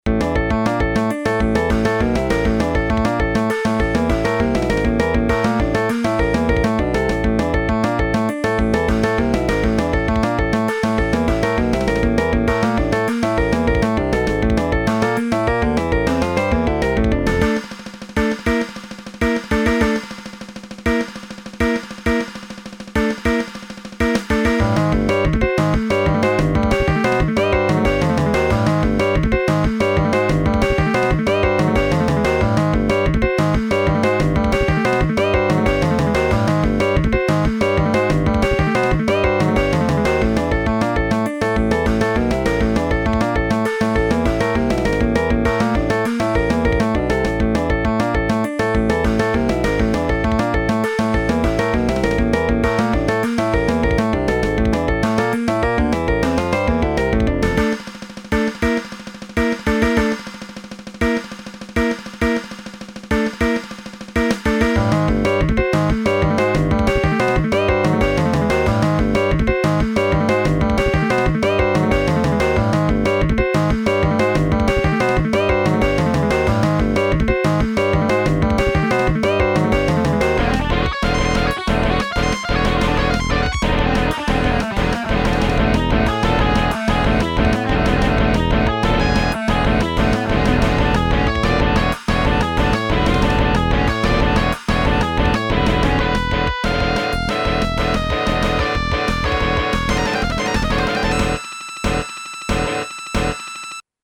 Ohmsägør, c'est une base metal ajoutant beaucoup d'éléments étrangers, du poprock au bebop en passant par le classique baroque, le death, le prog, la salsa.
La musique reste accessible mais particulièrement casse tête et technique, avec moults changements de tempo et harmonisations dangereusement...délicates, je dirais.
EDIT: J'ai rajouté une conversion midi en mp3, je sais que tout le monde n'a pas un midi génial donc ca donnera une idée un peu plus claire j'espère.